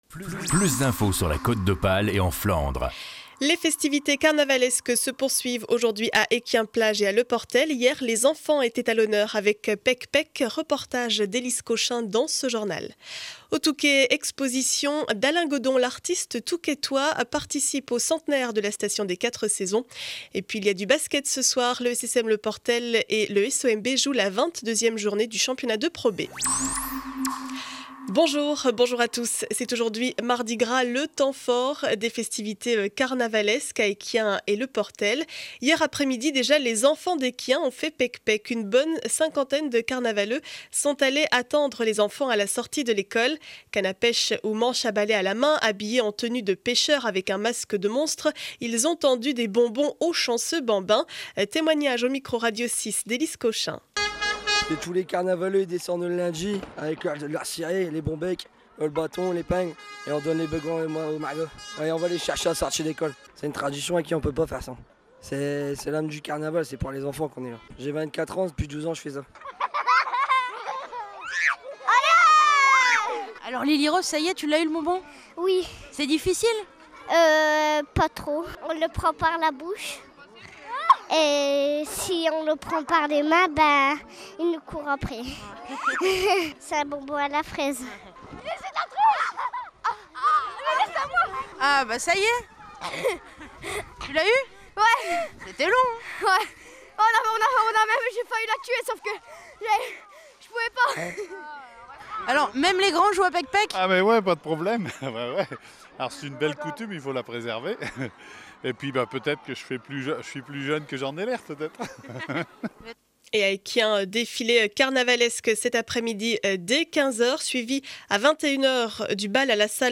Journal du mardi 21 février 2012 7 heures 30 édition du Boulonnais.